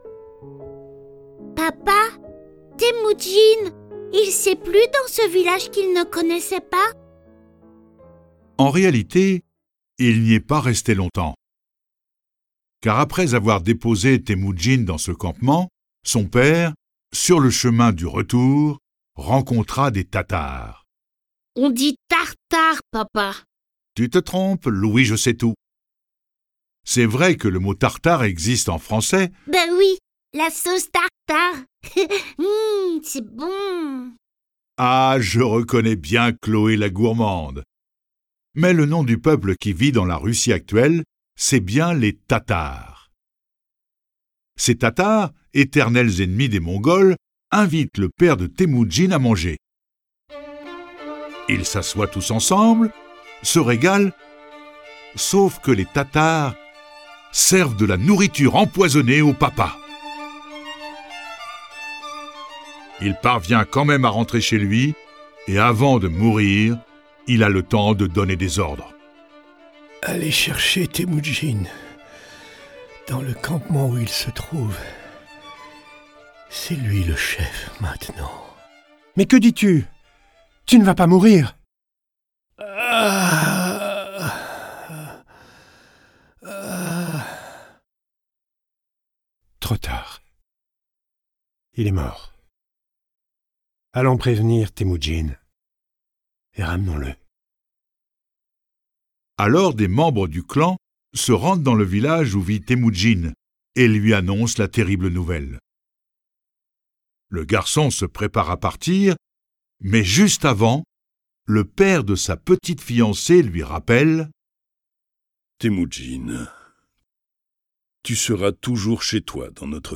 Diffusion distribution ebook et livre audio - Catalogue livres numériques
Il prend alors le nom de Gengis Khan (« roi universel » en mongol). À la fin de son règne, le guerrier légendaire contrôle une grande partie de l'Asie jusqu'aux frontières de l'Europe. Le récit de sa vie est animé par 7 voix et accompagné de plus de 30 morceaux de musique classique et traditionnelle.